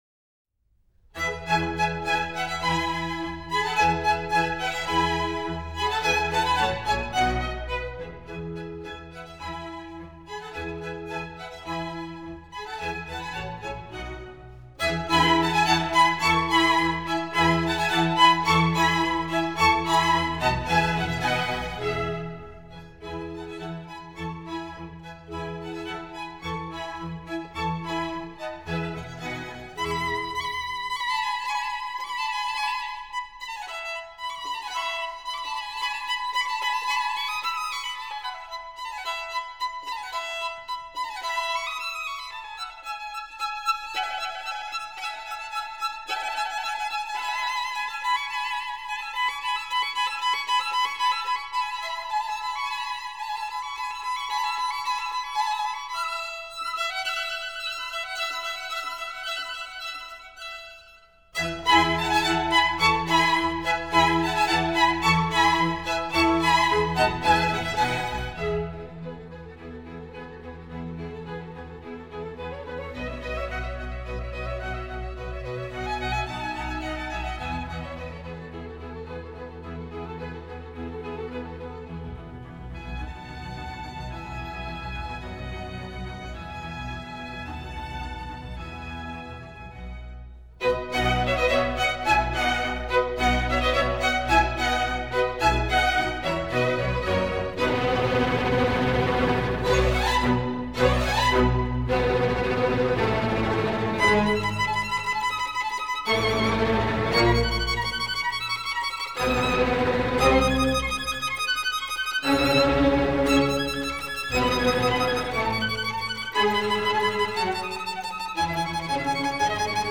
小提琴
E大调，作品第8号，第一首